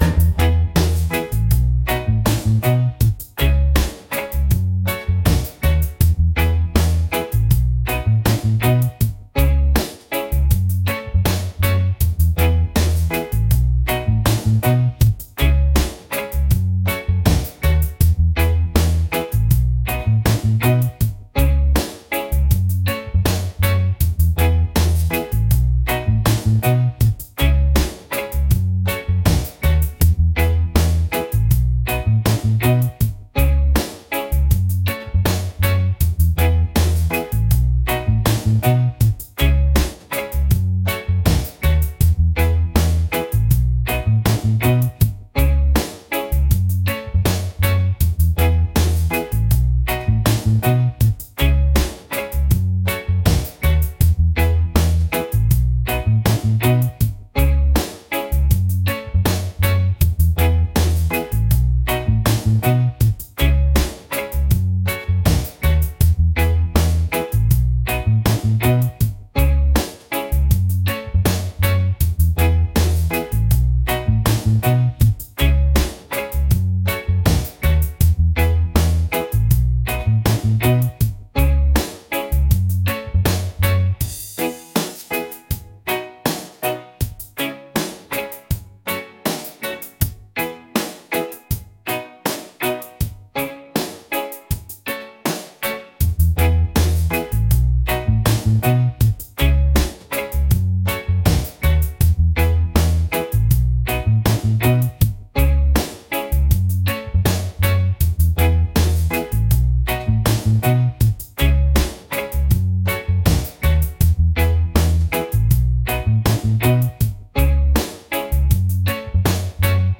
laid-back | mellow | reggae